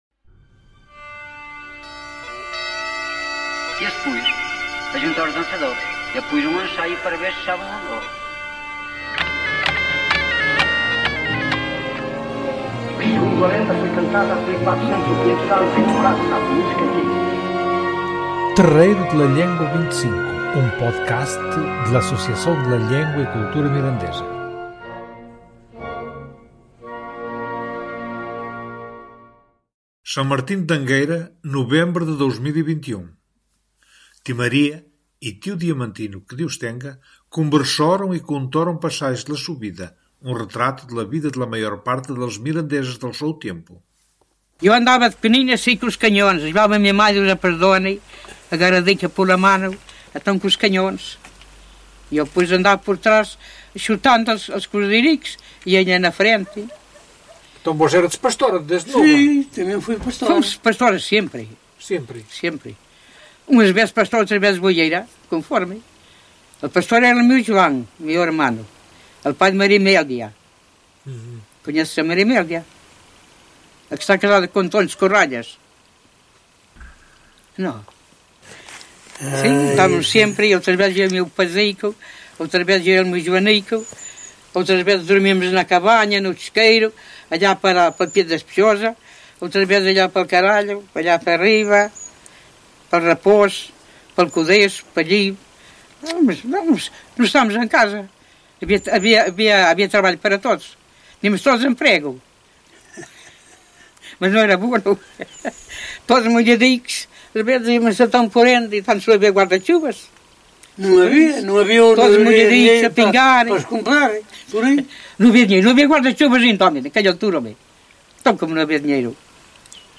San Martino de Angueira, nobembre de 2021.